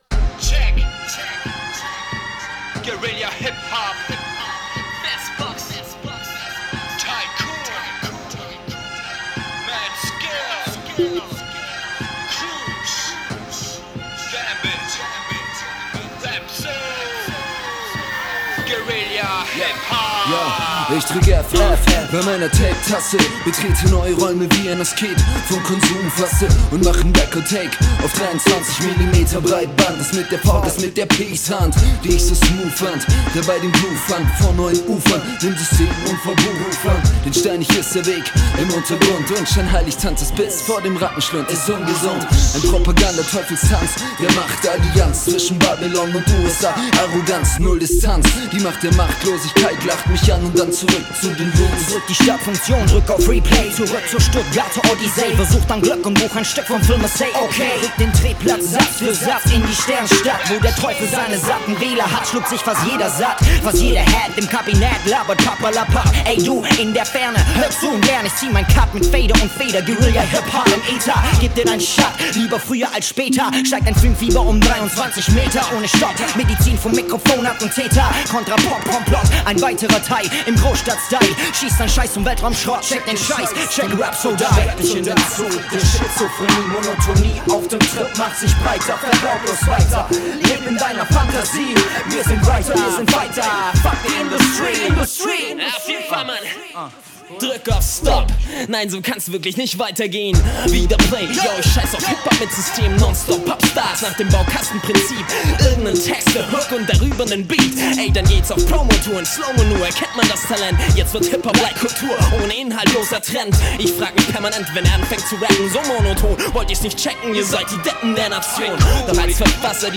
Download Hiphop cc